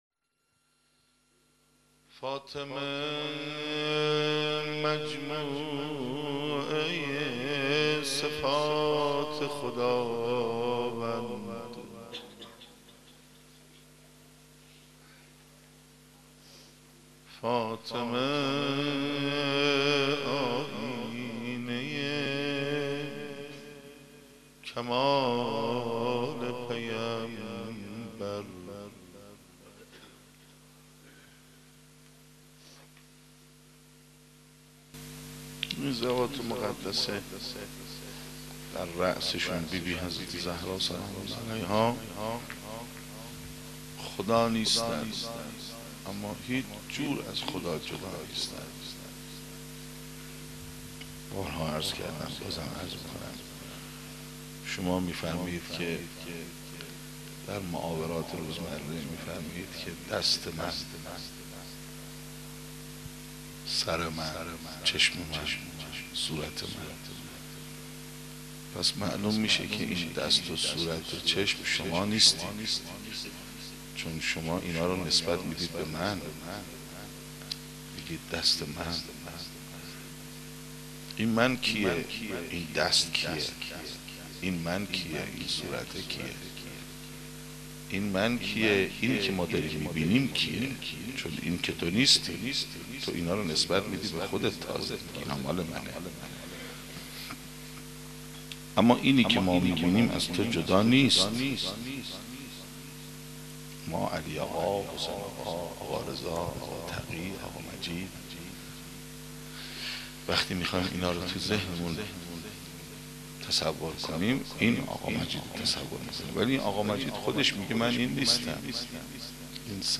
با اینکه پیکر پسرش بوریا شود روضه محمود کریمی